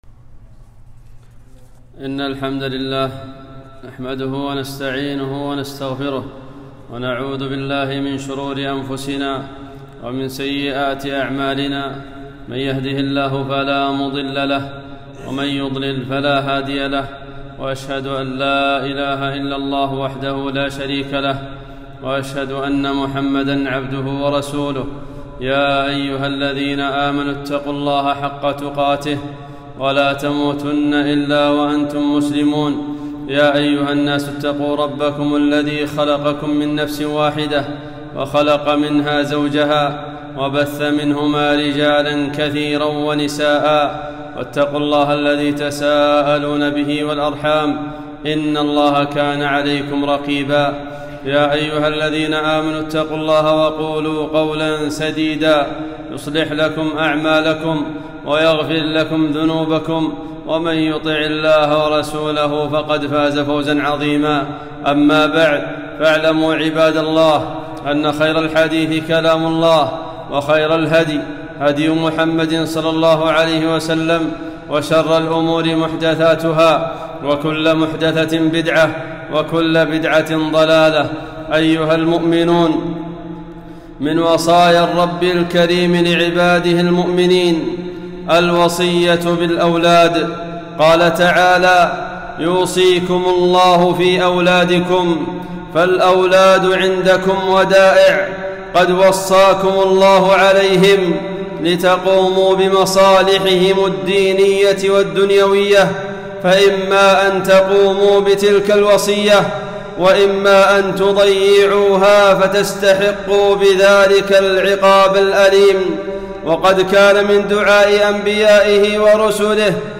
خطبة - تربية الأولاد